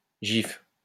In French, the acronym tends to be pronounced [ʒif]
,[6] with the voiced postalveolar fricative, [ʒ], as in the j in the French joie or the s in the English measure or vision, even though [], which does not occur in native vocabulary, tends to be retained in English loanwords (such as jeans).[7] Some languages lack English's soft and hard g sounds in their phonologies; Spanish and Finnish, for example, lack [ʒ] in their native words.[8] In Norwegian, GIF is pronounced with a hard g, [ɡ],[9] unlike native words, for which the sequence ⟨gi⟩ would be pronounced with a voiced palatal approximant, [j], like the y in English yes.[10]